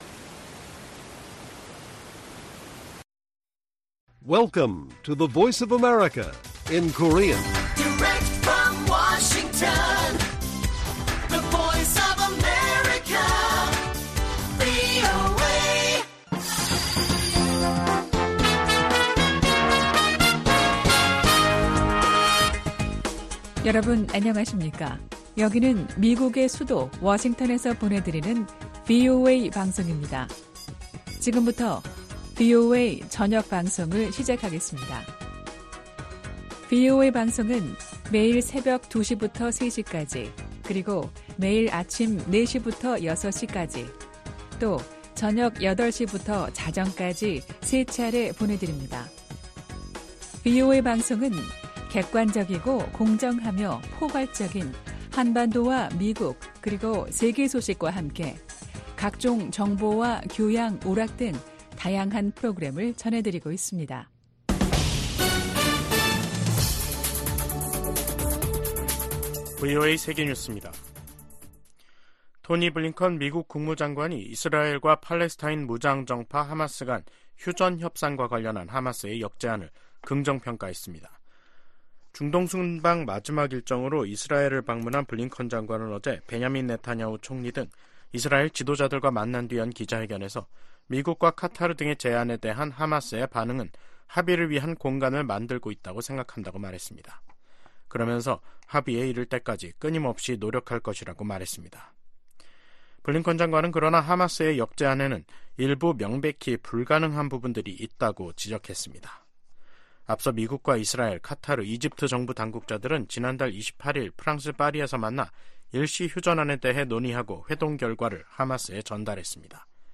VOA 한국어 간판 뉴스 프로그램 '뉴스 투데이', 2024년 2월 8일 1부 방송입니다. 윤석열 한국 대통령은 북한 정권이 비이성적인 정치세력임으로 이에 대비, 더 튼튼한 안보를 구축해야 한다고 강조했습니다. 미국 정부는 북한-러시아 군사협력이 강화되면서 북한 대량살상무기 개발을 진전시킬 것이라고 우려했습니다.